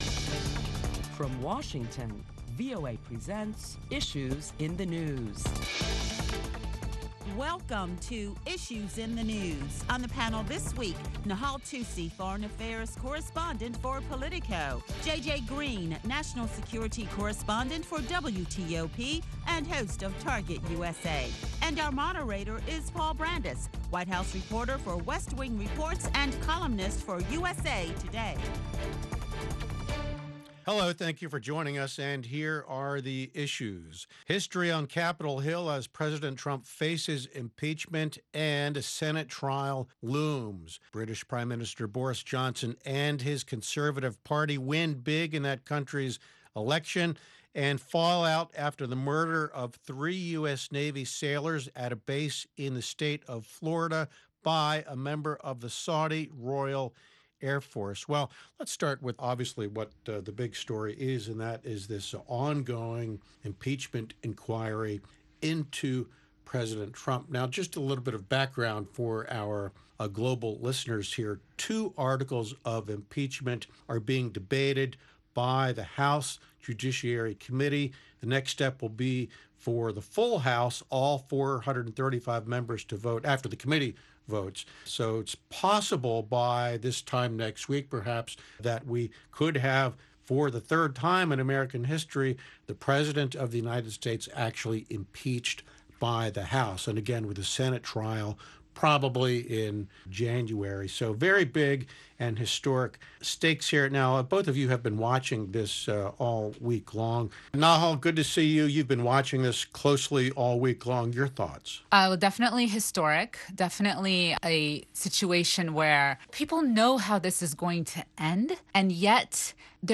Listen to a panel of prominent Washington journalists as they deliberate the latest top stories that include the progression of the impeachment process of President Donald Trump.